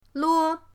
lo1.mp3